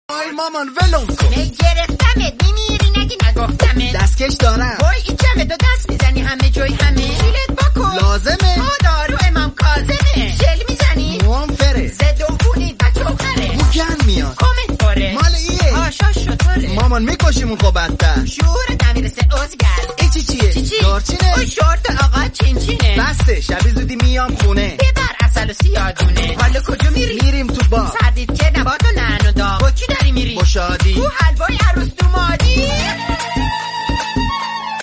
آهنگ طنز